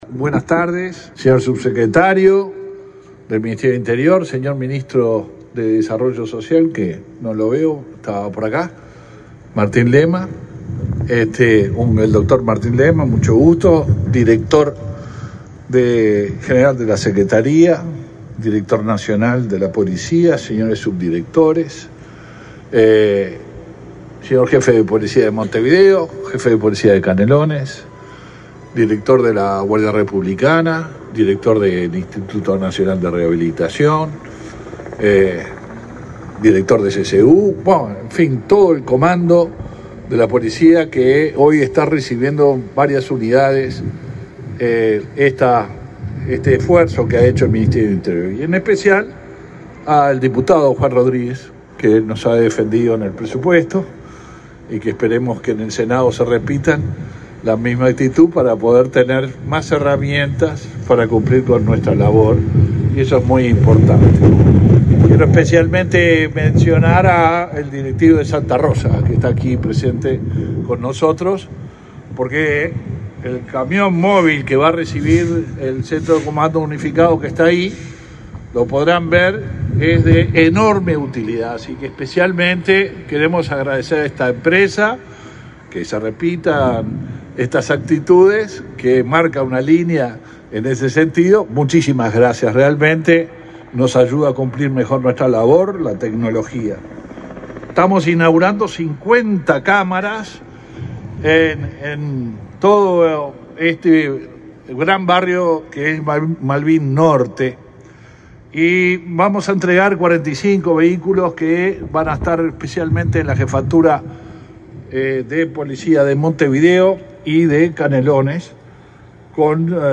Palabras del ministro del Interior, Luis Alberto Heber
Con la presencia del ministro del Interior, Luis Alberto Heber, quedó en funcionamiento, este 18 de agosto, el nuevo comando móvil en el barrio Malvín